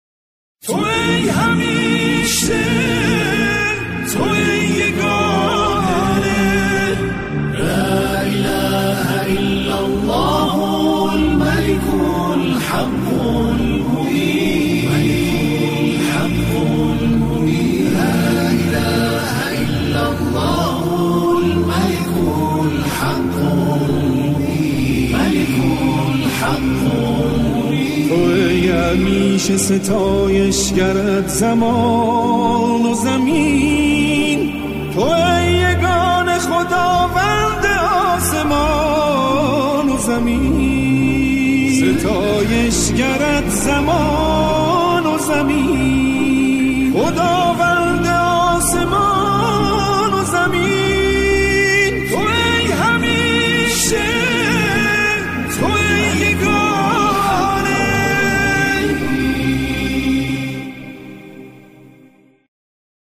آکاپلا